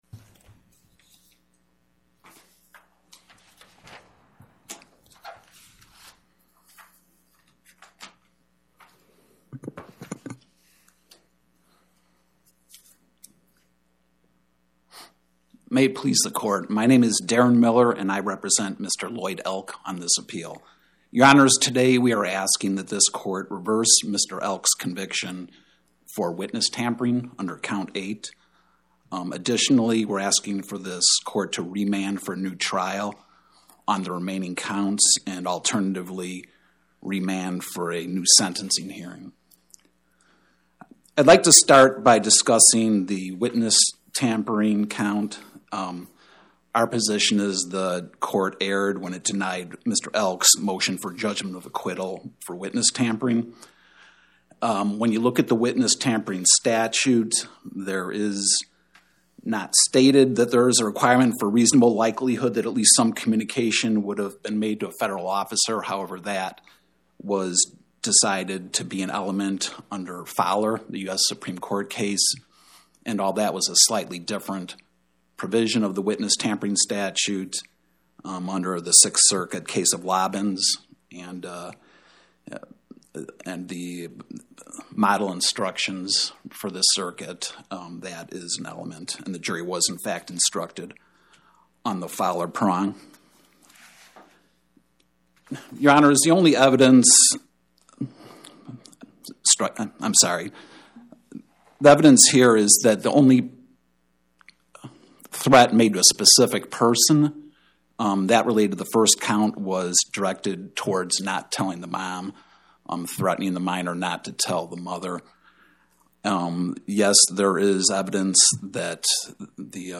Oral argument argued before the Eighth Circuit U.S. Court of Appeals on or about 10/24/2025